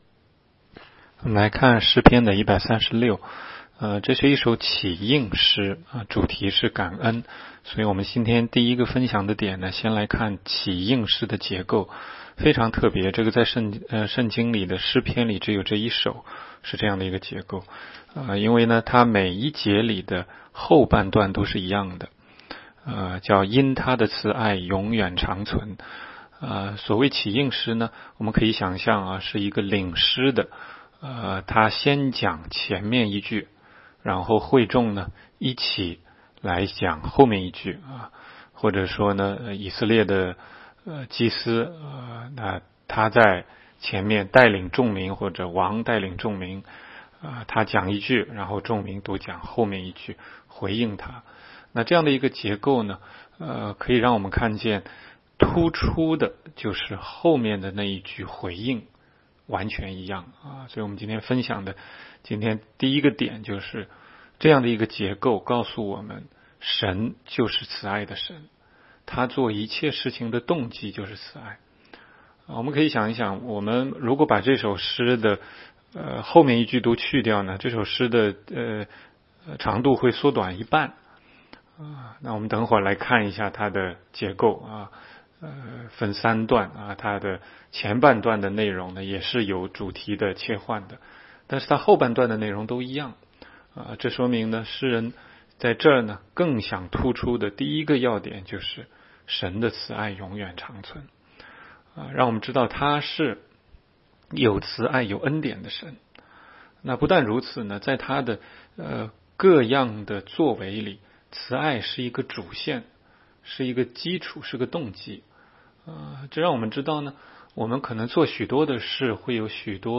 16街讲道录音 - 每日读经 -《 诗篇》136章